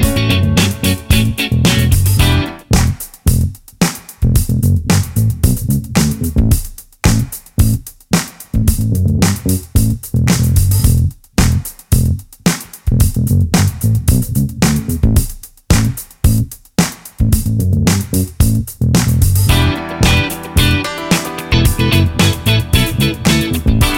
no BV R'n'B / Hip Hop 3:56 Buy £1.50